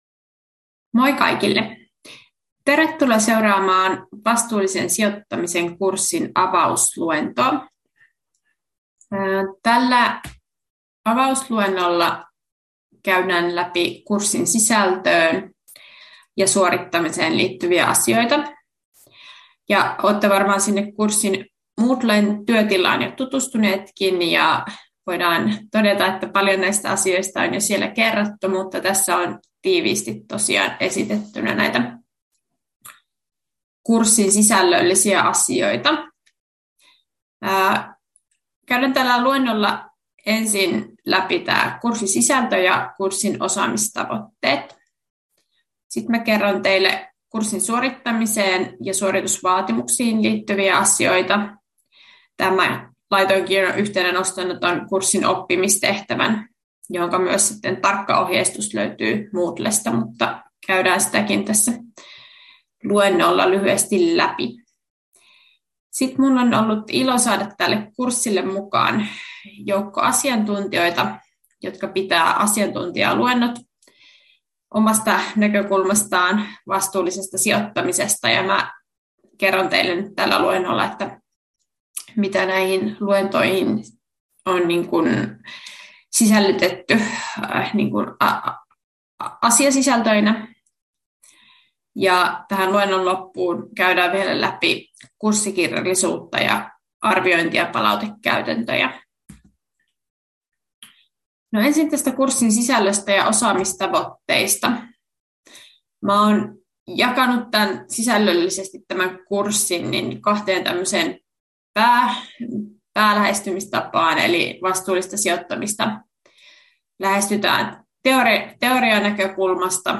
Avausluento 2025-2026